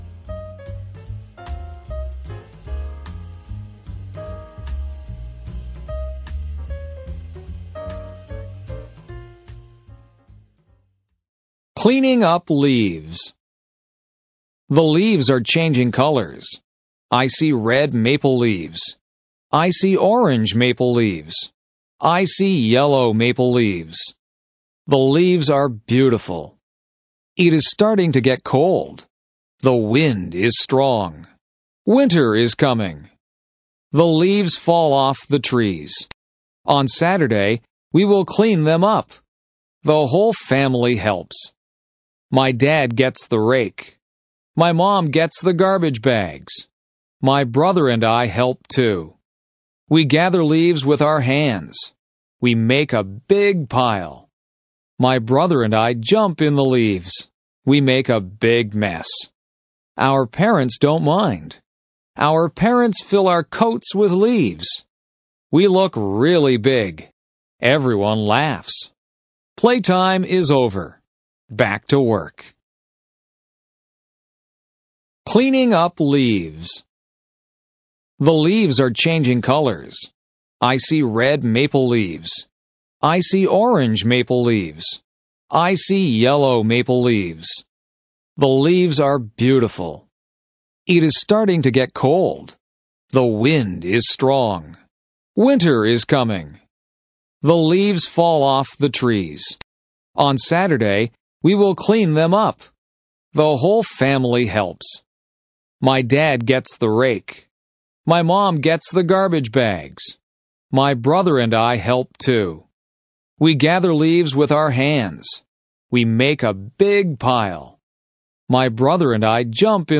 This article is a beginner-friendly English listening practice passage designed for junior and senior high school students. Through a family activity of cleaning up fallen leaves, it helps learners understand everyday English and improve their listening skills.